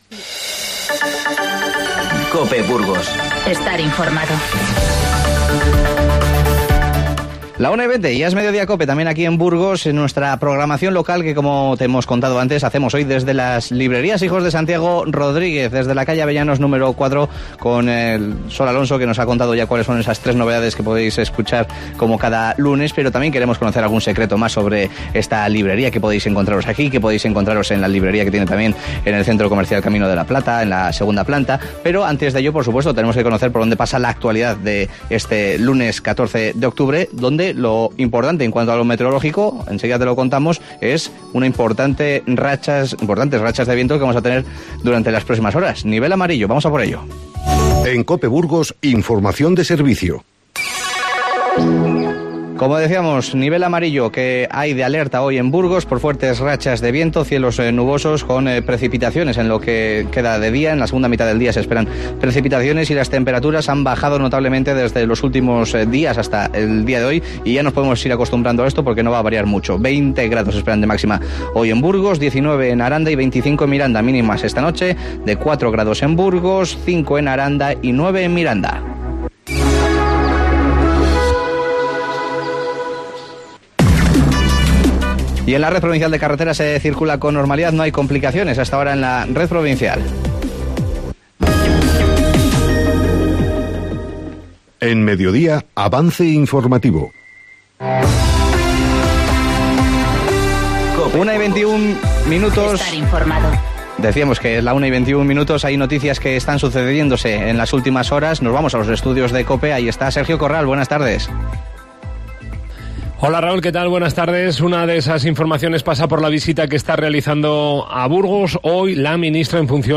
Continuamos desde Librerías Santiago Rodríguez, conociendo más detalles de sus nuevas instalaciones.